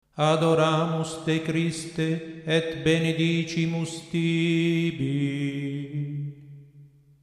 • l’accentus, detto anche tono di lezione;
L'accentus consiste nella lettura intonata (ossia, "su un tono") del testo sacro.
Ne risulta una declamazione "fissa", statica, priva delle normali inflessioni della voce.